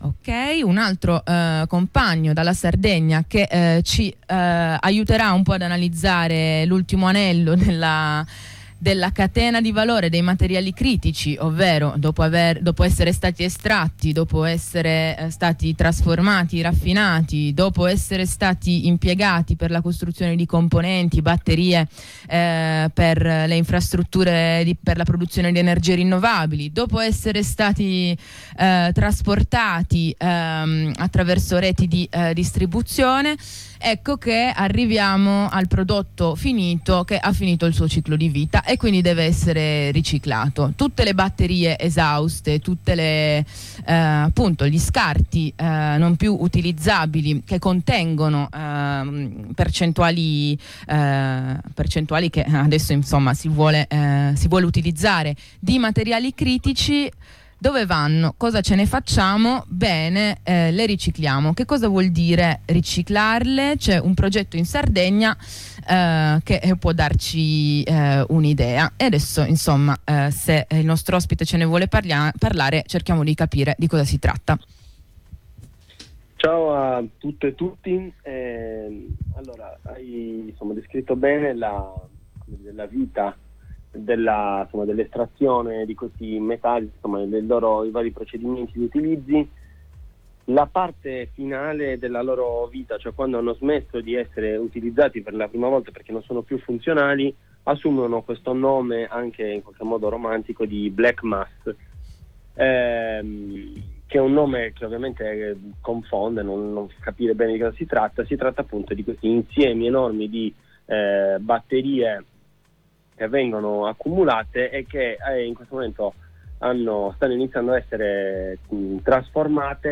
Miniere, impianti, reti: ne parliamo con un compagno, che ci dipinge come questi tre fattori della transizione energetica si intrecciano in Sardegna: